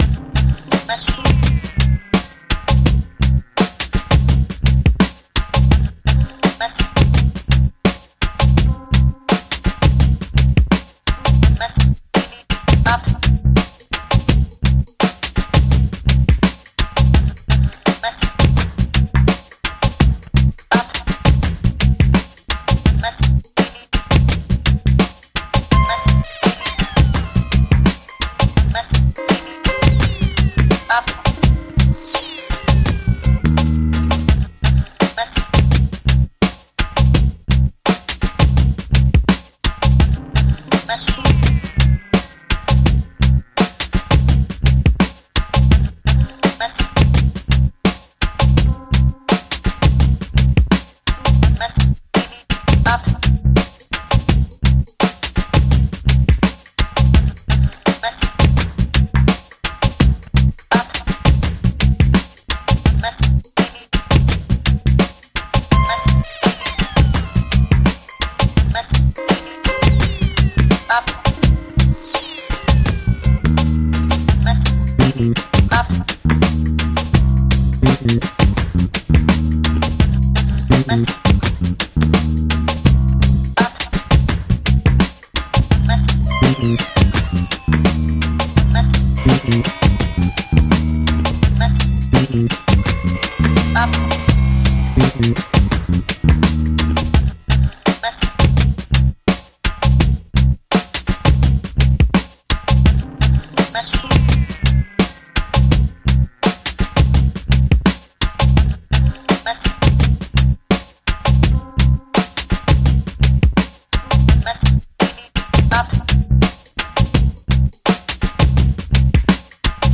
Dub, reggae, electronica, downtempo, chill out, trip hop.